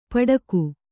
♪ paḍaku